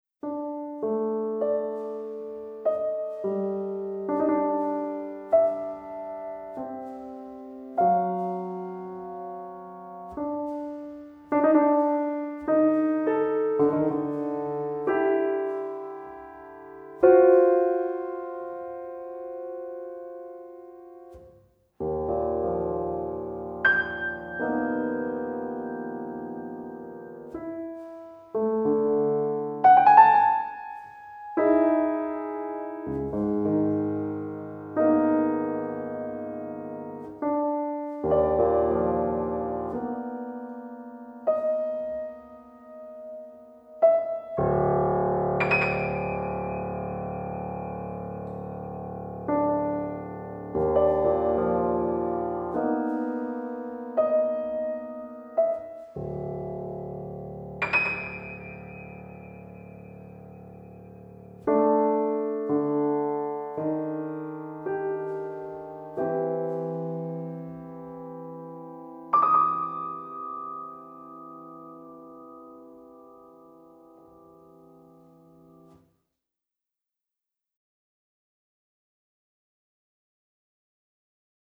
Piano with strings